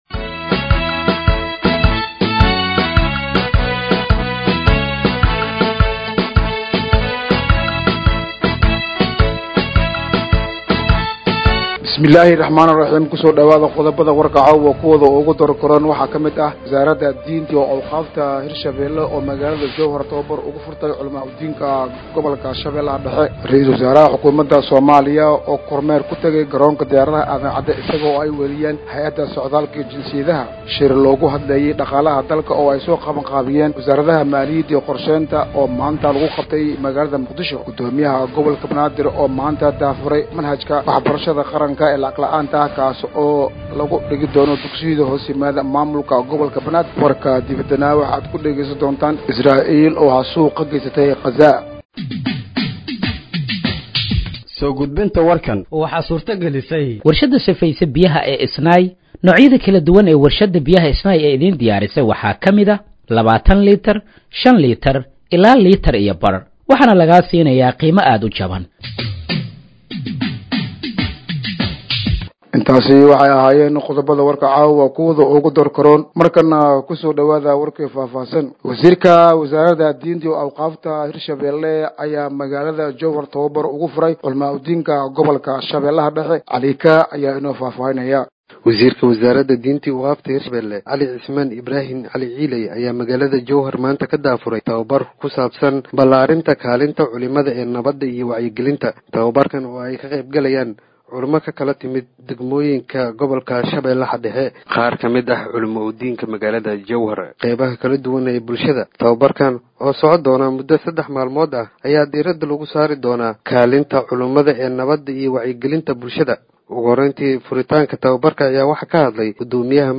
Dhageeyso Warka Habeenimo ee Radiojowhar 16/09/2025
Halkaan Hoose ka Dhageeyso Warka Habeenimo ee Radiojowhar